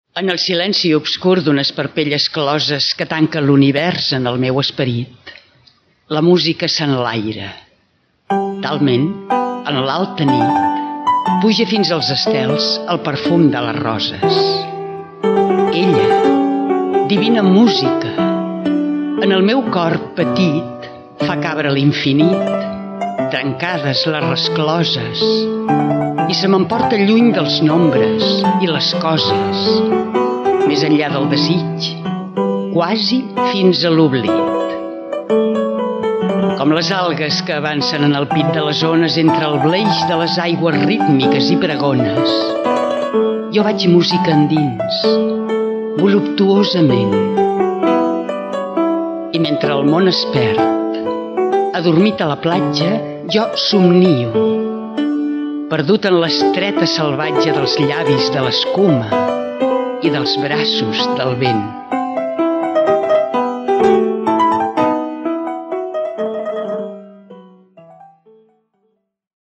Recitat: En el silenci obscur, de Marius Torres